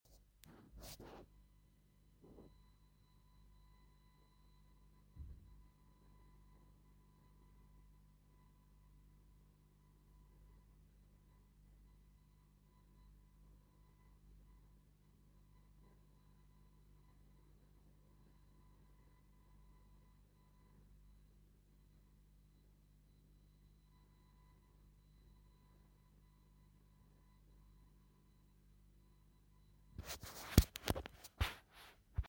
Le volume à zéro dB ou coupé, cela revient au même : le bruit est toujours là.
Voici le bruit en pièce jointe.
Ce qu'on entend au casque est un bruit de fond, une faible "ronflette".